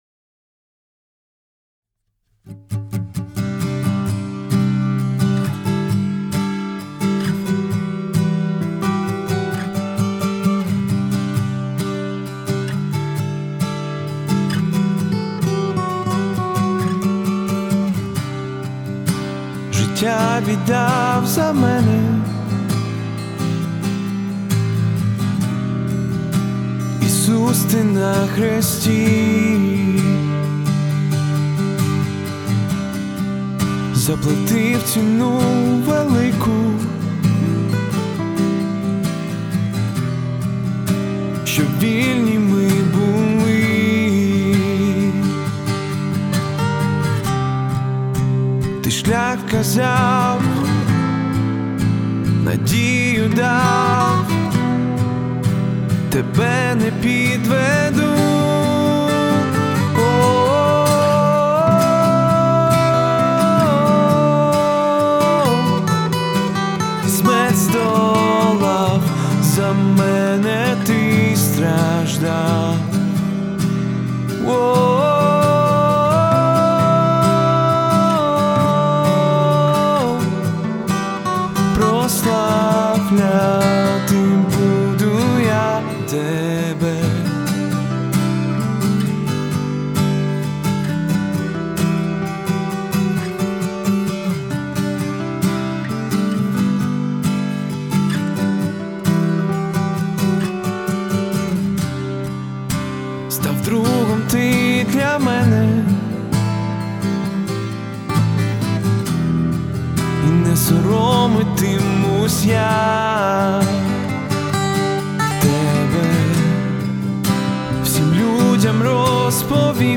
677 просмотров 425 прослушиваний 16 скачиваний BPM: 134